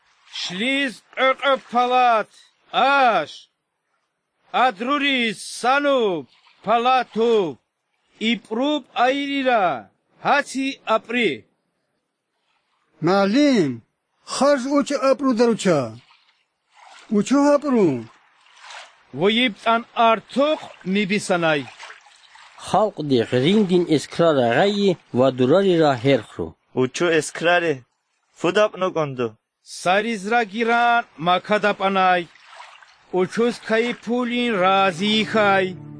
Definitely hearing some ejective consonants, like most autochthonous languages of the Caucasus.
May not Avar since I hear a few examples of ejective [p’].